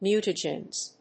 /ˈmjutʌdʒɛnz(米国英語), ˈmju:tʌdʒenz(英国英語)/